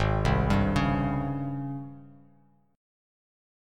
Listen to G#M11 strummed